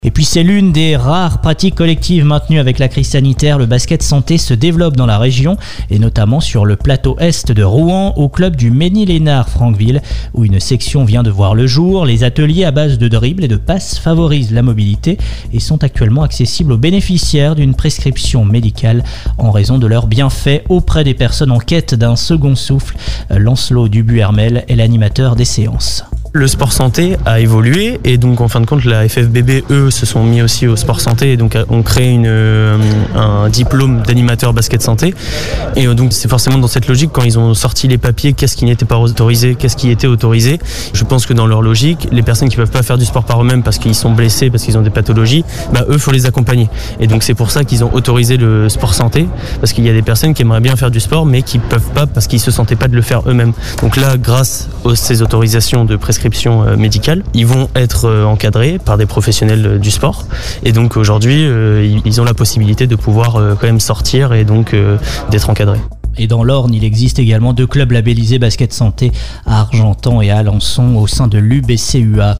Pour preuve, un journalise est venu interviewer notre responsable de cette activité ainsi que quelques participants.